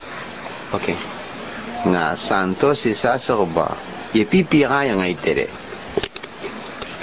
telling this true story from 1972 in Tobian